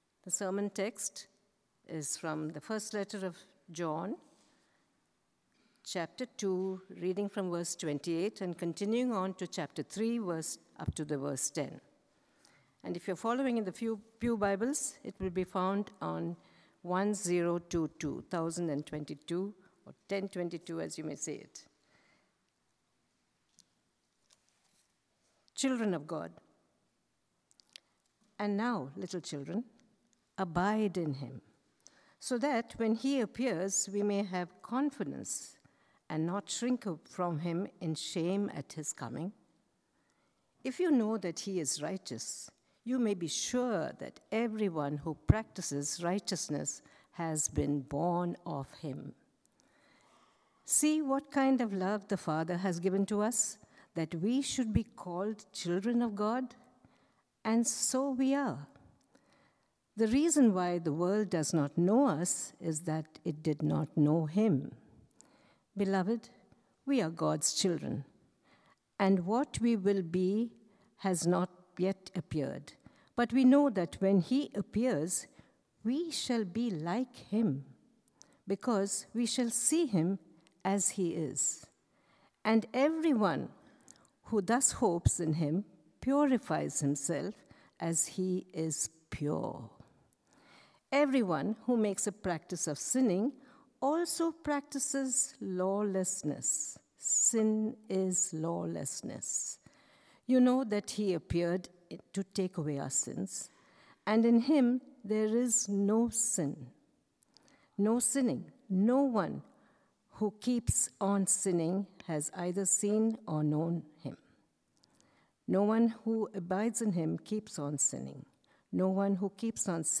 Stone Hill Church of Princeton sermons and special events.